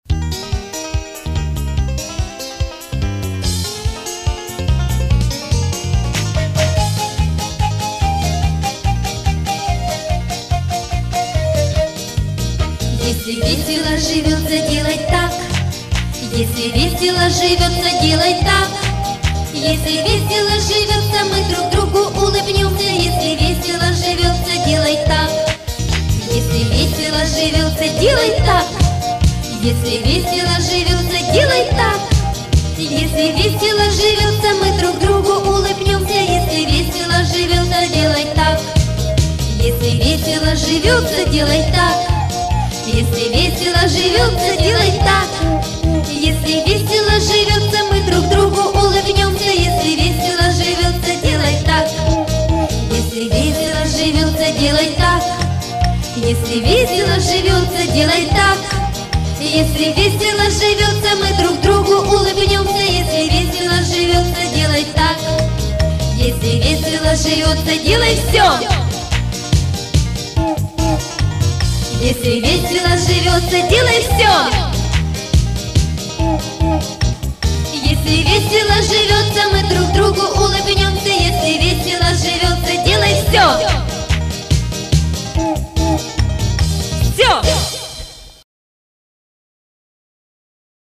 Полно вариантов. Вот...энергичное.